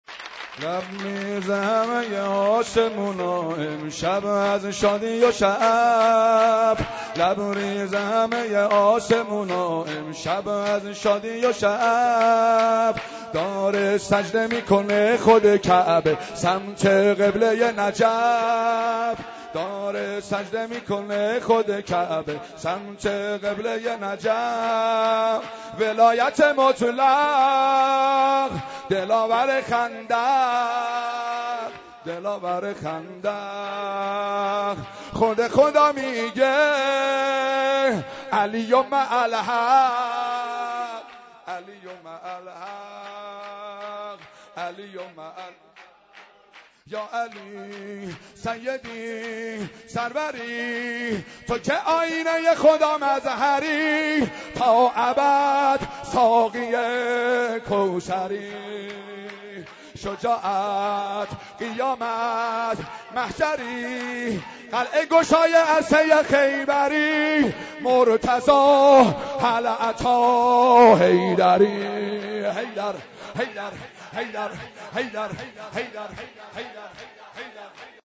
سبک سرود دوم